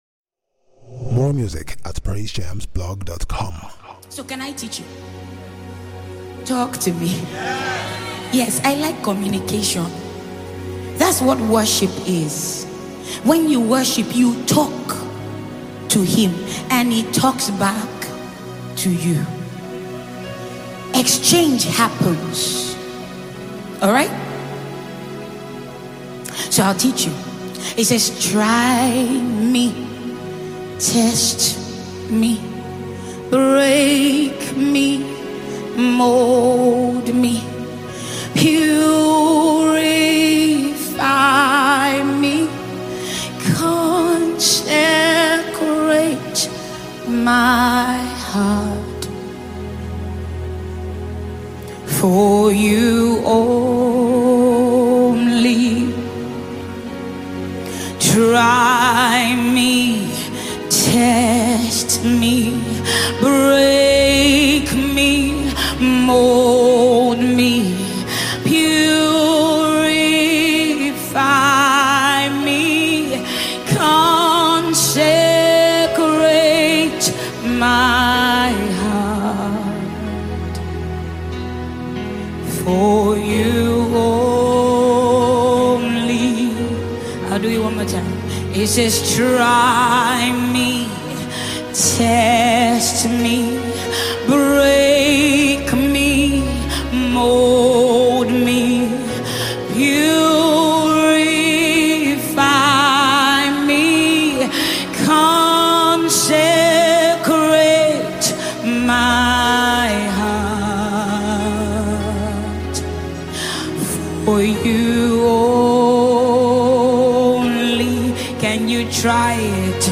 live performance worship session